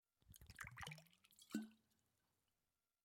Lantern_Reload.wav